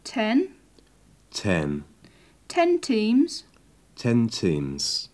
Вы уже познакомились с двумя основными тонами английской речи — нисходящим и восходящим.
Нисходящий тон Восходящий тон
Произнесите, подражая образцу.